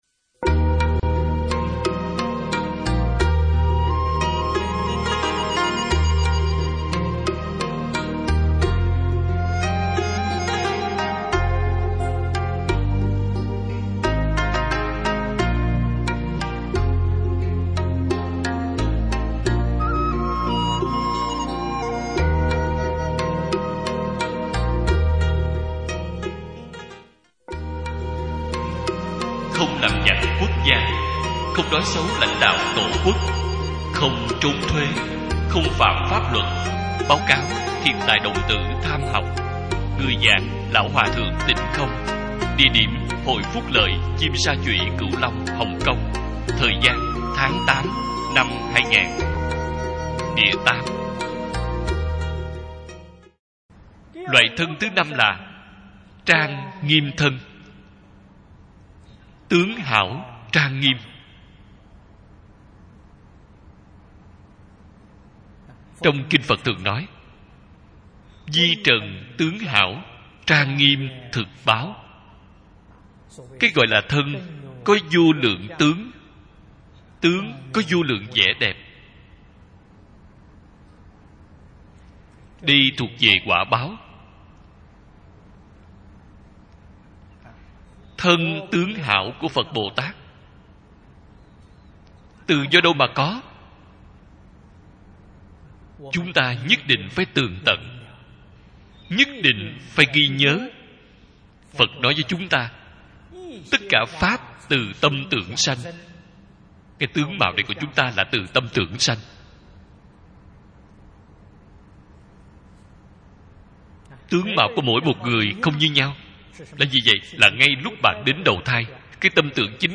Không Làm Giặc Quốc Gia - Tập 6 - Bài giảng Video